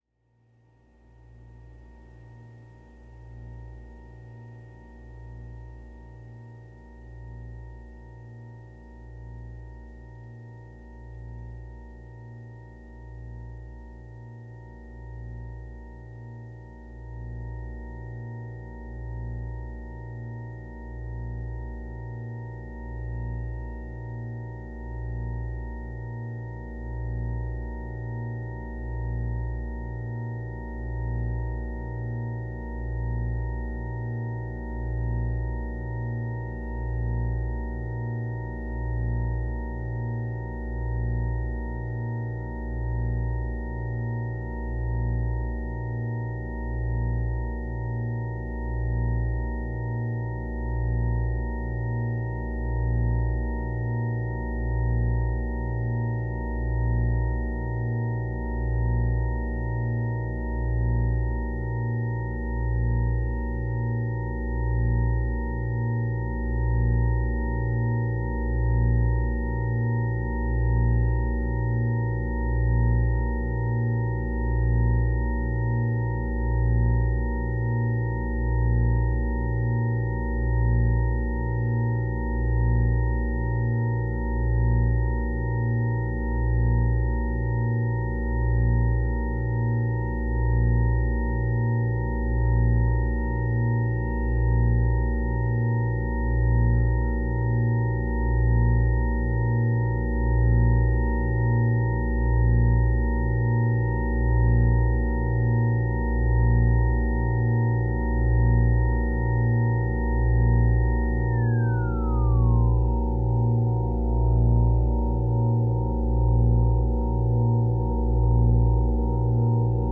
trio work for electronics, movement and projections
Cellist and composer
ambient-dub-cutup music
techno-noise-spirit-movement work